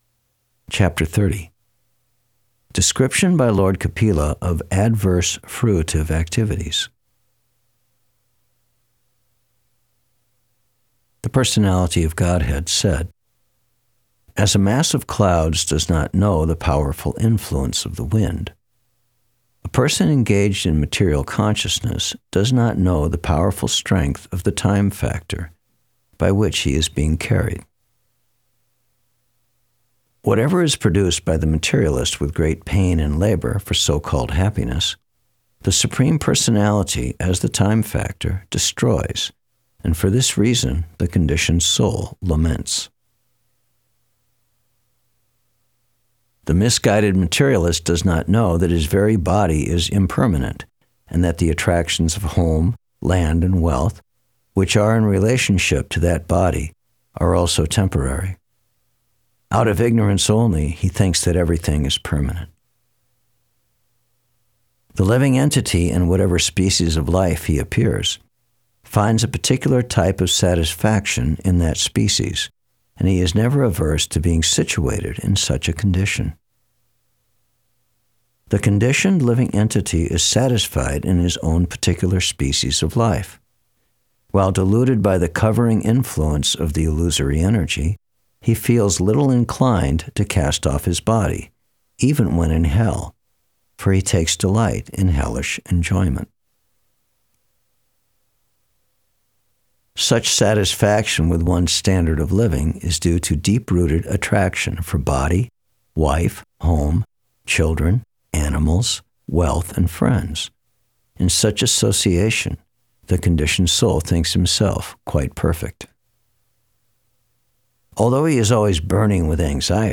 Thank you very much for this clear reading =) Really appreciate it.
Ch_30_SB_3rd_Canto_Verses_Only.mp3